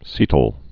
(sētl)